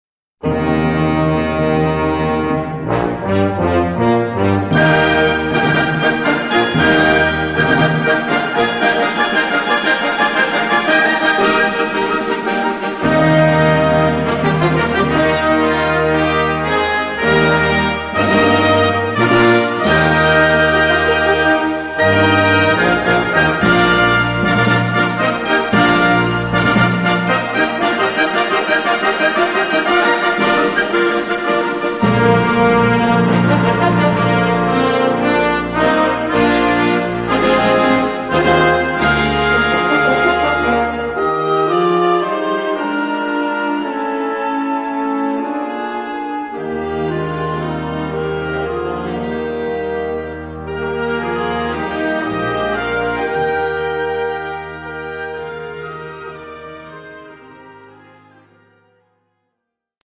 Gattung: Eröffnungsmusik
A4 Besetzung: Blasorchester Zu hören auf